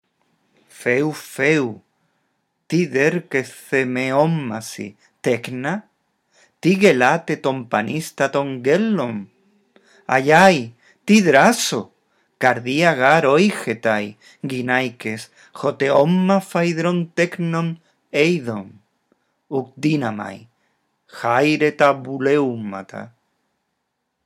Lee el texto en voz alta, respetando los signos de puntuación.